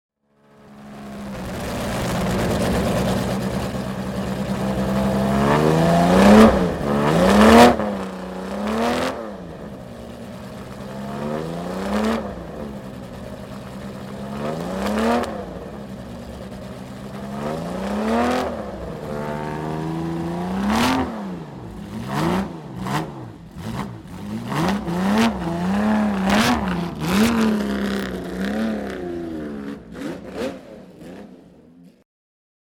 Maserati Tipo 63 (Birdcage)
Maserati_Tipo_63.mp3